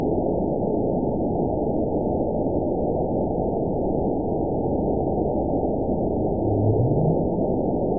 event 920494 date 03/27/24 time 23:28:42 GMT (1 year, 2 months ago) score 9.29 location TSS-AB10 detected by nrw target species NRW annotations +NRW Spectrogram: Frequency (kHz) vs. Time (s) audio not available .wav